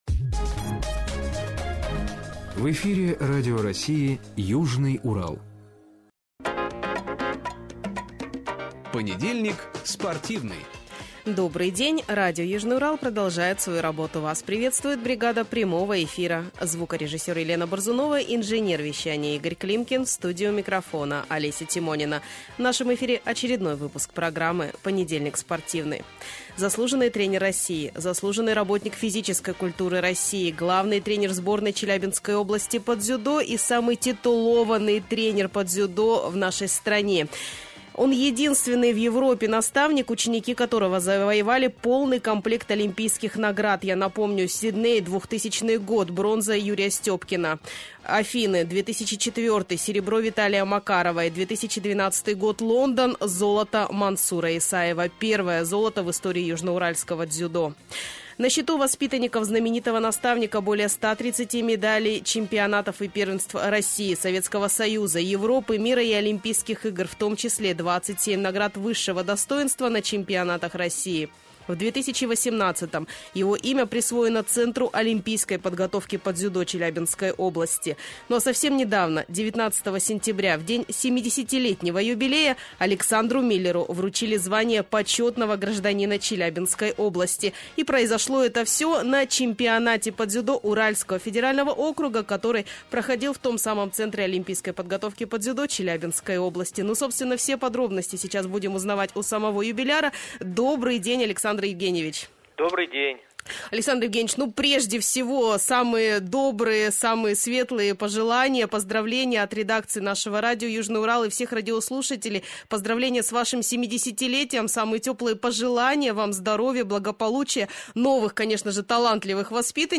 гость прямого эфира радио "Южный Урал"